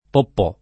poppò [ popp 0+ ] → popò